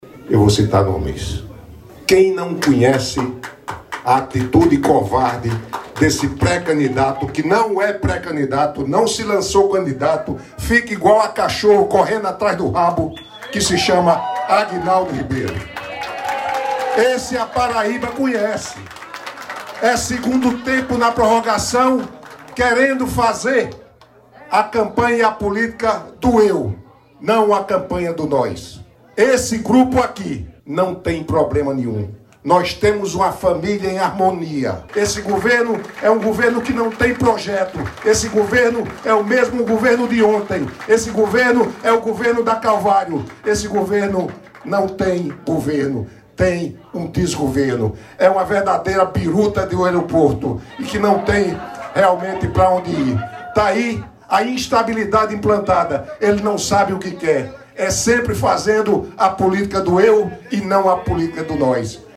Evento do Partido Liberal, realizado em João Pessoa, foi marcado por fortes declarações.